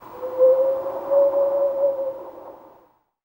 TUV NOISE 01.wav